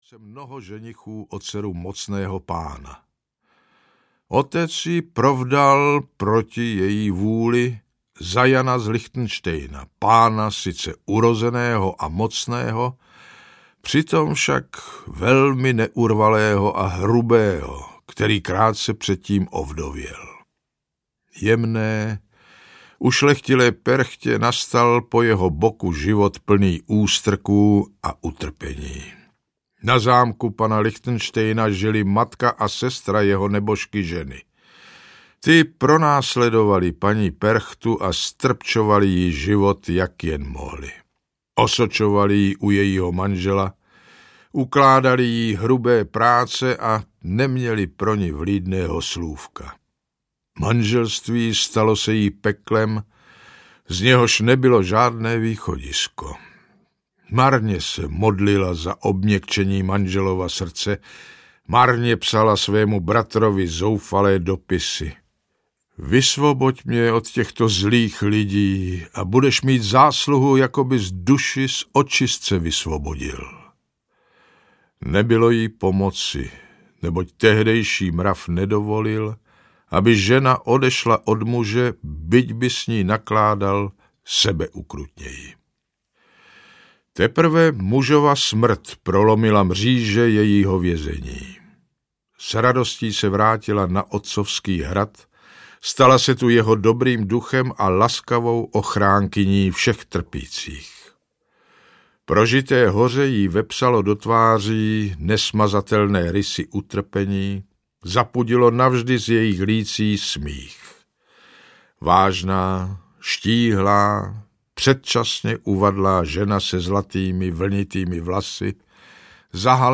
Herec Jan Kanyza vypráví příběhy z různých míst Čech i Moravy plné strašidel a nadpřirozených bytostí.
Ukázka z knihy
Herec Jan Kanyza je považován za jednoho z nejcharismatičtějších českých herců a jeho výrazný a charakteristický hlas se skvěle hodí pro interpretaci historických textů.